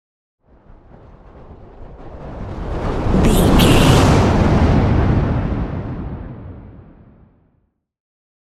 Whoosh fire large
Sound Effects
dark
intense
whoosh